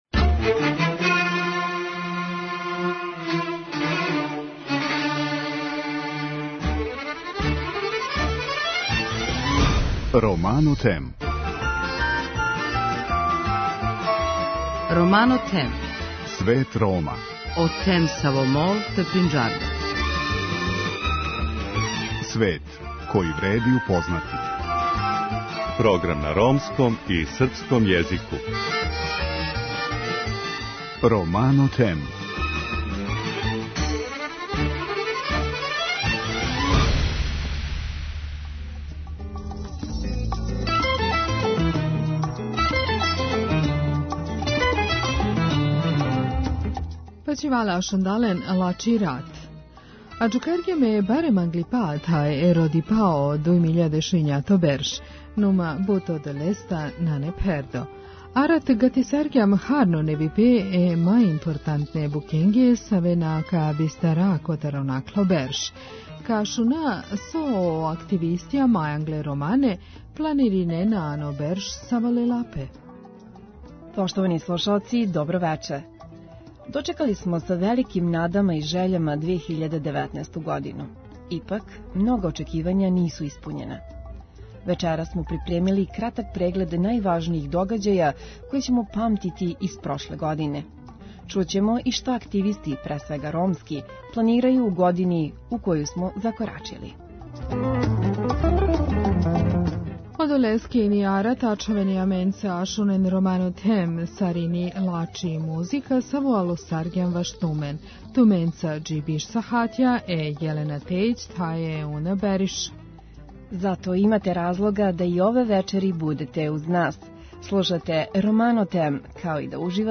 О томе ће говорити активисти, из ромске заједнице пре свега .